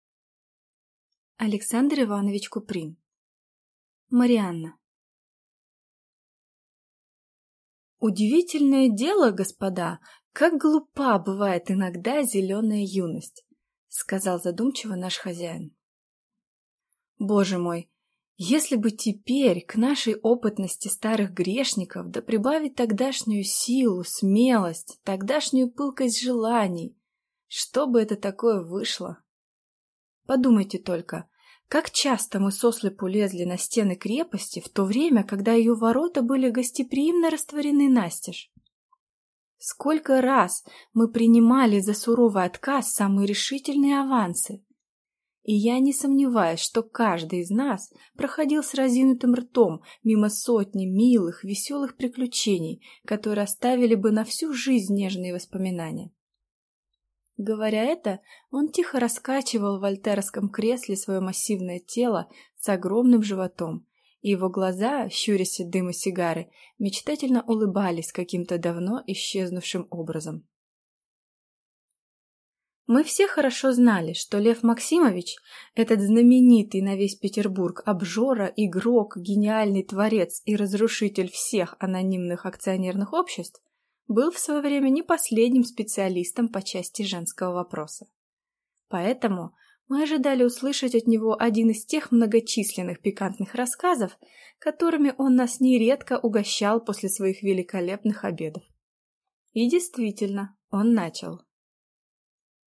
Аудиокнига Марианна | Библиотека аудиокниг